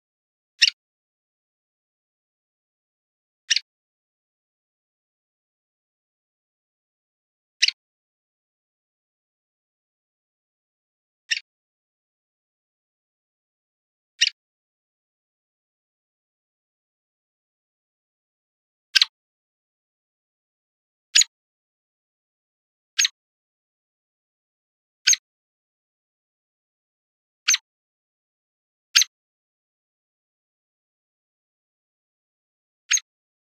Hairy Woodpecker | Ask A Biologist